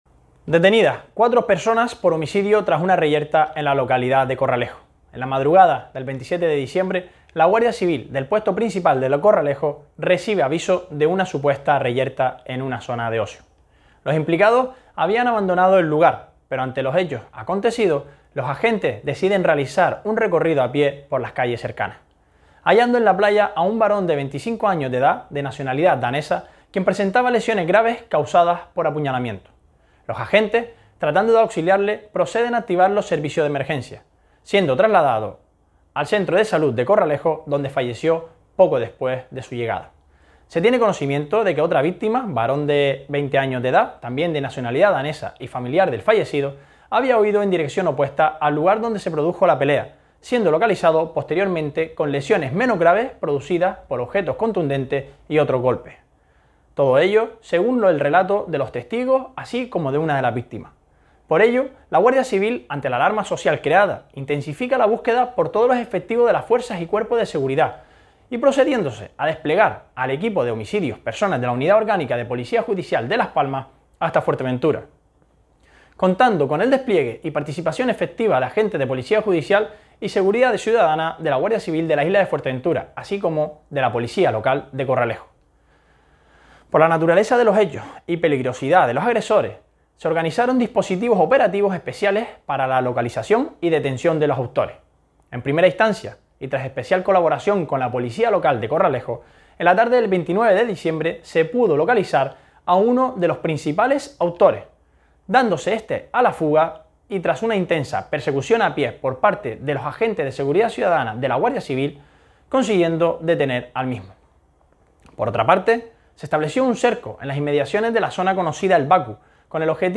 Entrevistas y declaraciones
La Guardia Civil relata la investigación tras el homicidio de un turista danés en Corralejo